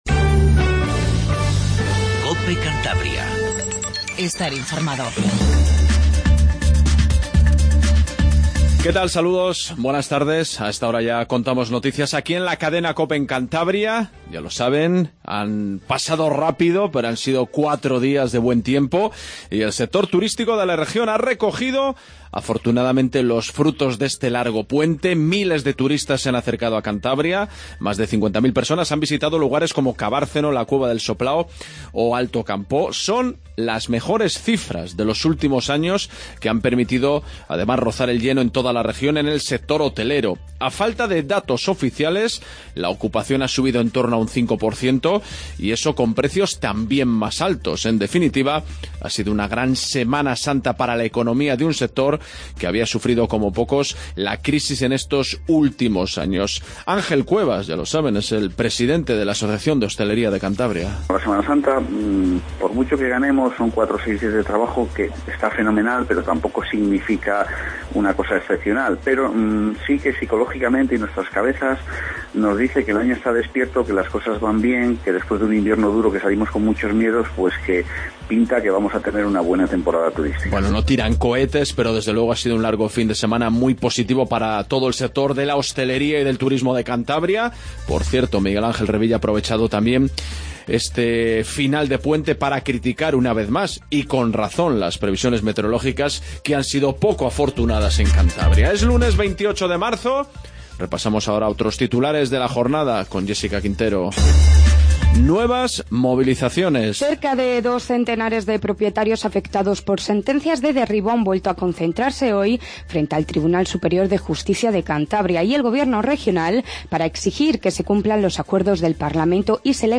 INFORMATIVO REGIONAL 28 DE MARZO